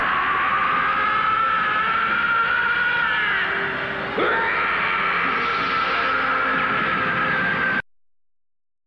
Trunks screaming and turning Super Saiyajin